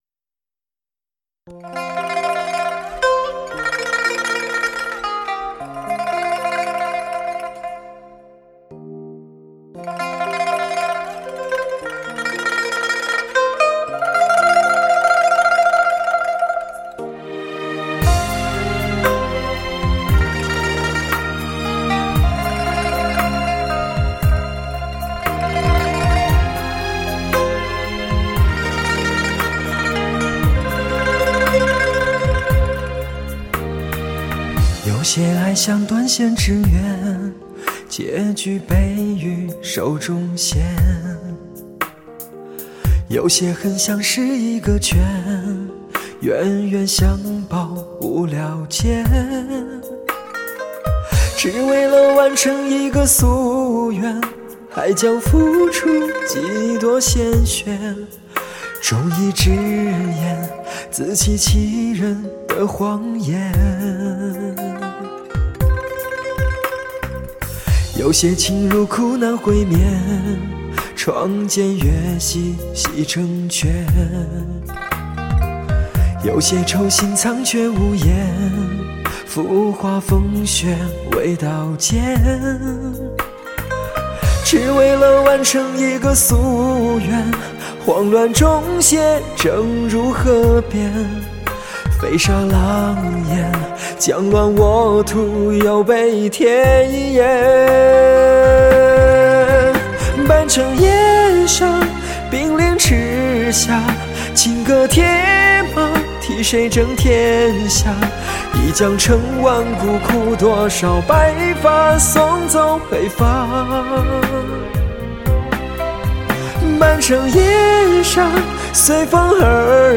史上最HI-FI震撼的发烧靓声......